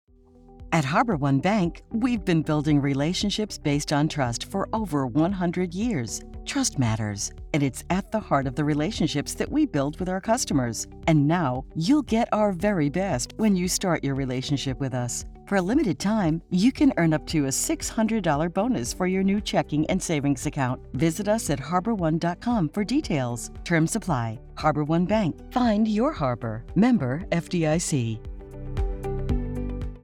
Female
My voice is very versatile, warm, conversational, and real.
Radio Commercials
Words that describe my voice are Warm, Conversational, Sophisticated.